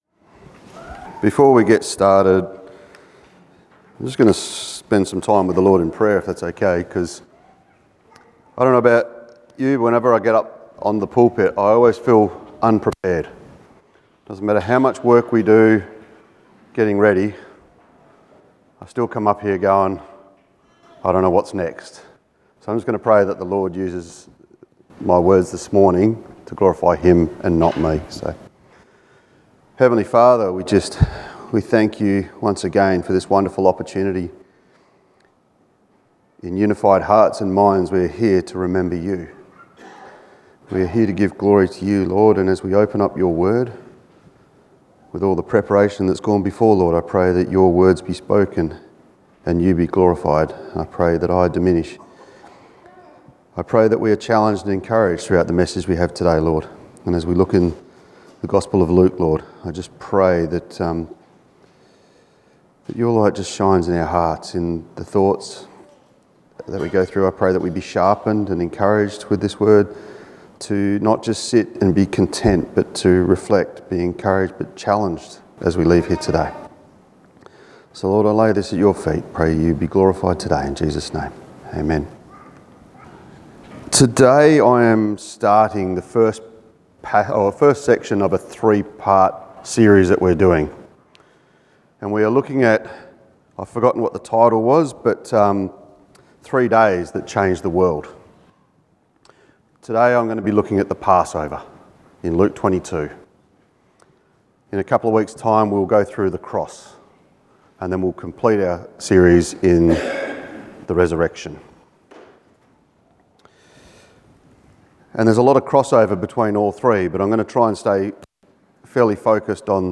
Passage: Luke 22:7-23 Service Type: Family Service